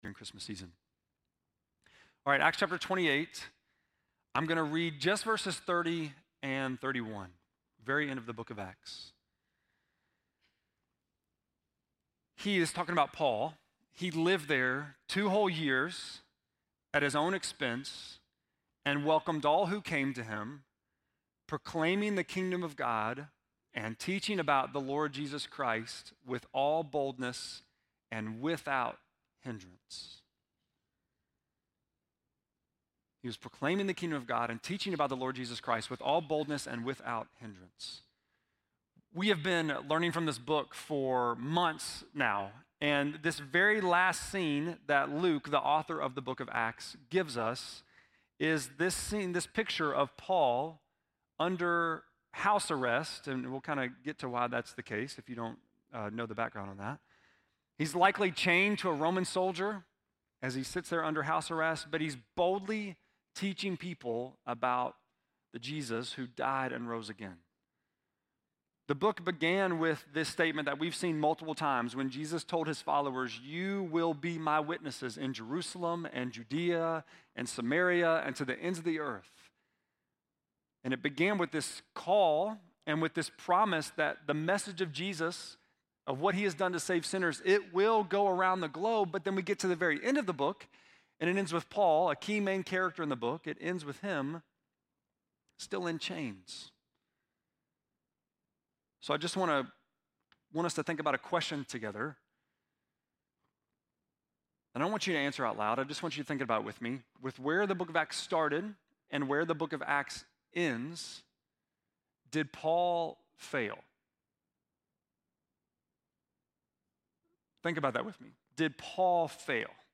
11.24-sermon.mp3